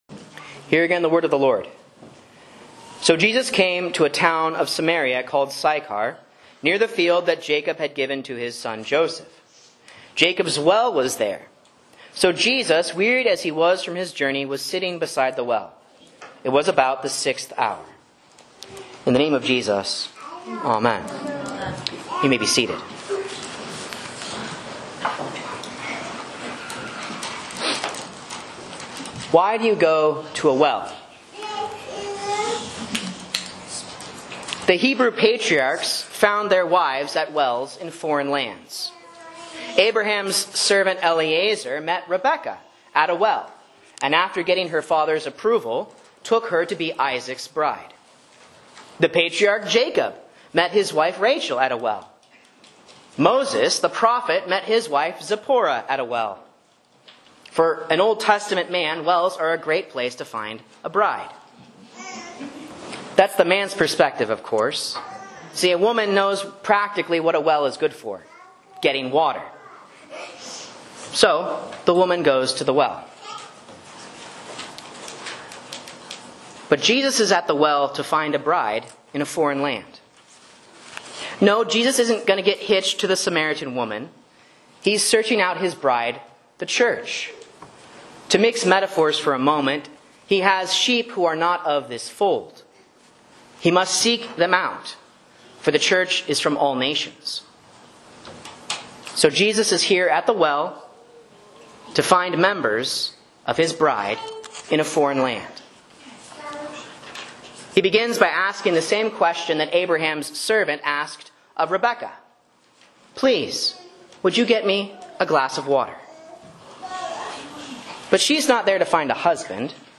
Sermon and Bible Class Audio from Faith Lutheran Church, Rogue River, OR
A Sermon on John 4:5-26 for Lent 3 (A)